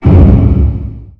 thud2.wav